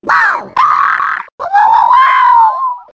One of Wiggler's voice clips in Mario Kart 7